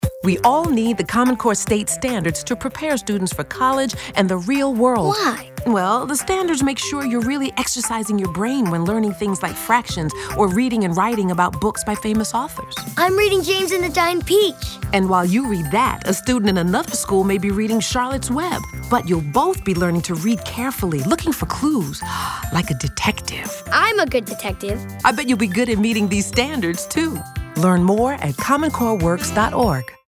Radio PSAs
Conversation - English Language Arts.mp3